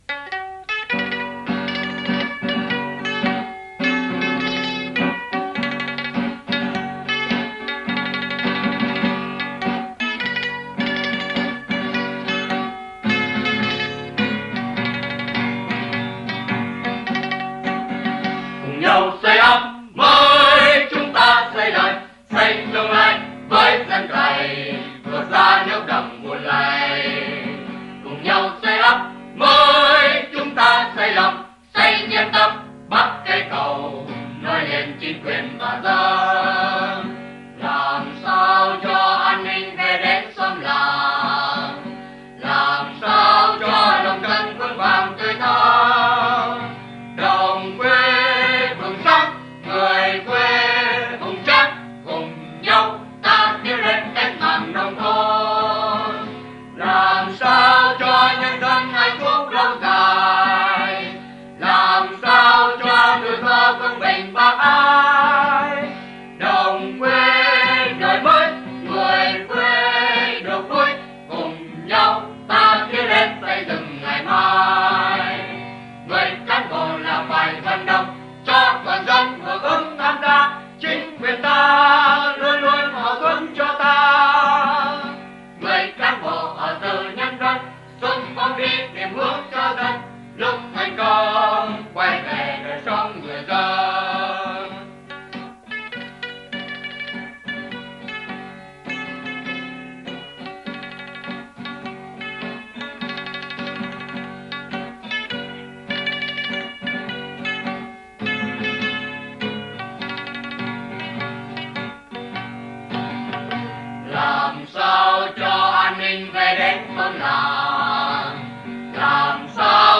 hợp xướng